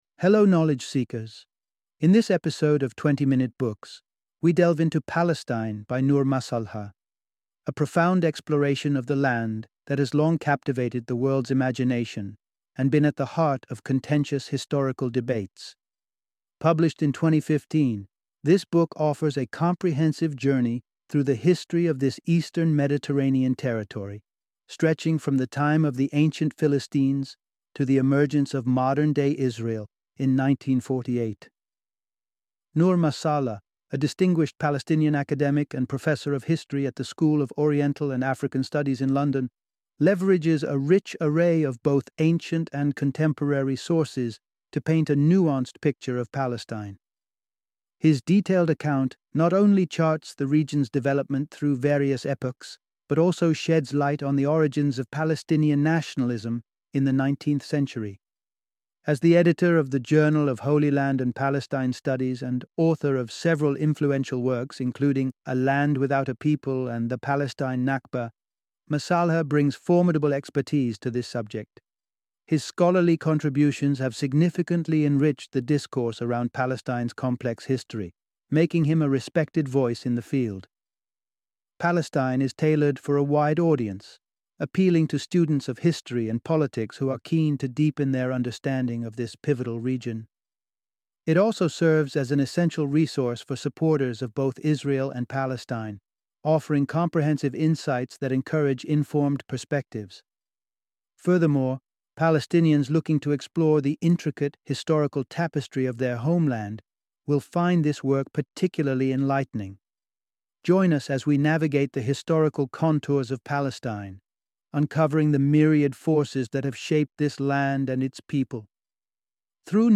Palestine - Audiobook Summary